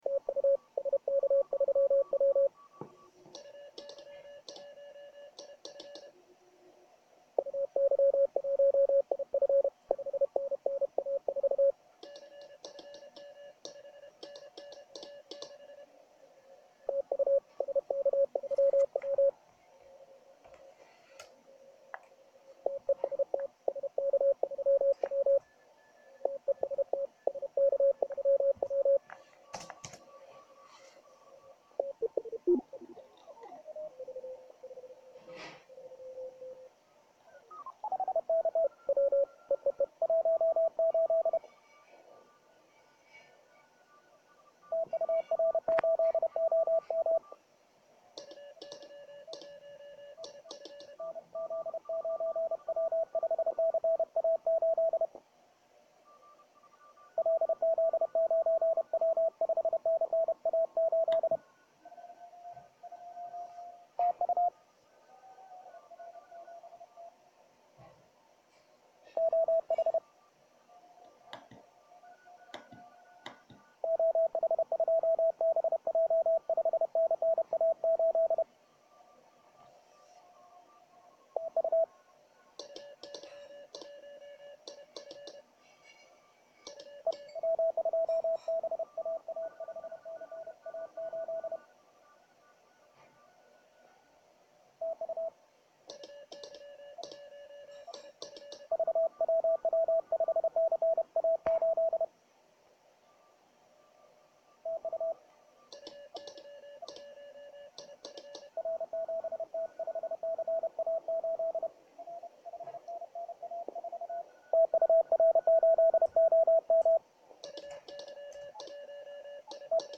На записях диктофоном, слышно как отвечают на 80 милливатт:
CQWWQSO.mp3